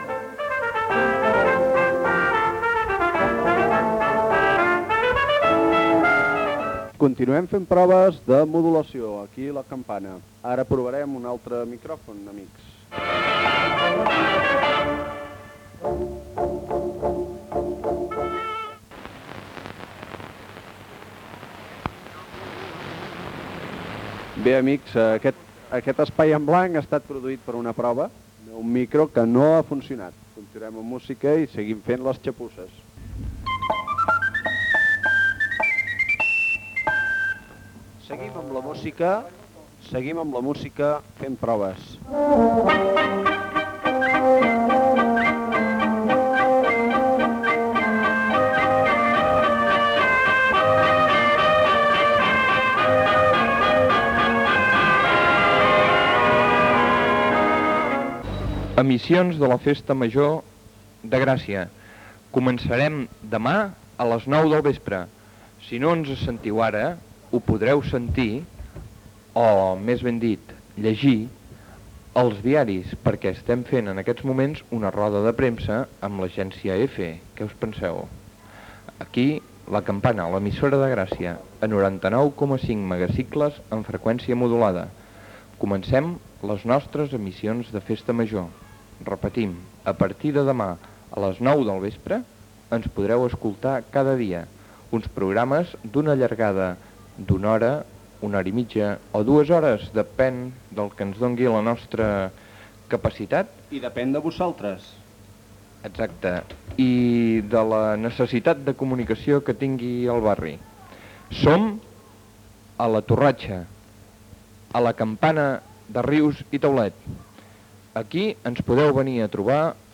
Emissió en proves abans dels programes especials de Festa Major de Gràcia 1979.
Emissió feta des de la torratxa o torre del rellotge de la Plaça de la Vila de Gràcia.